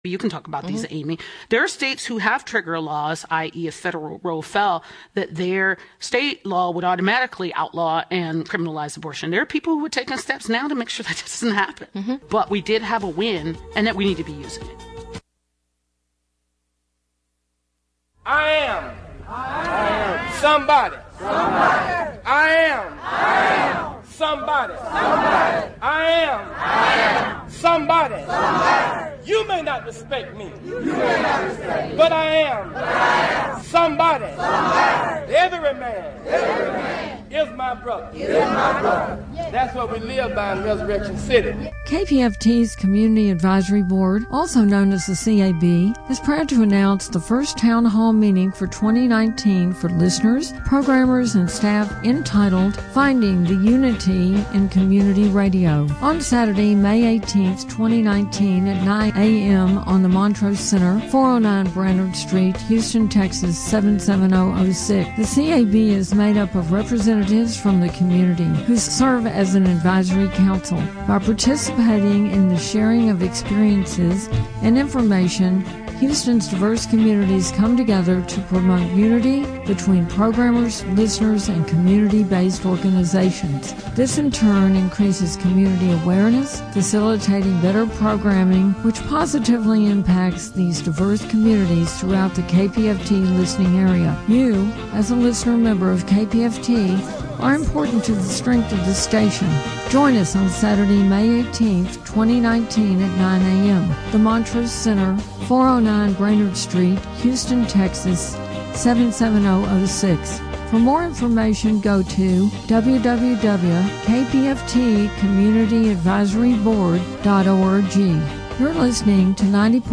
Today’s show is a fundraising show, so, with apologies, we can’t take on-air phone calls,
Listen live on the radio, or on the internet from anywhere in the world!